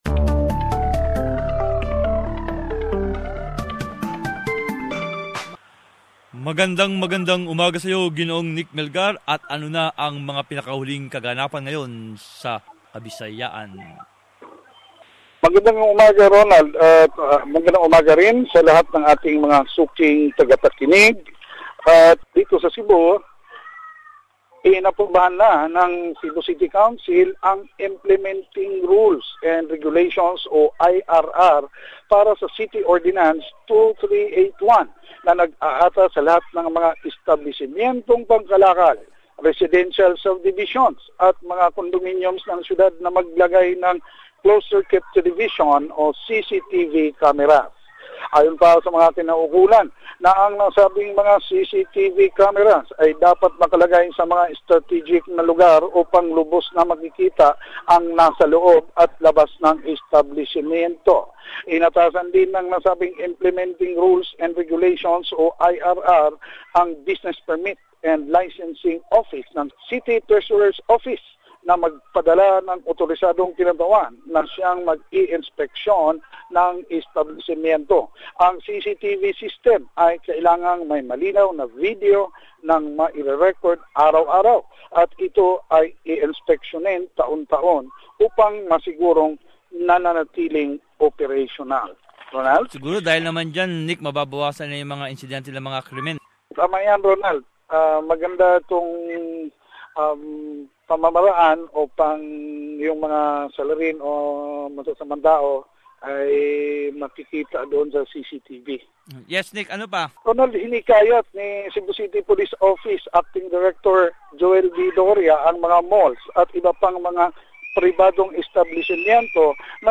Balitang Bisaya. Summary of latest news from the region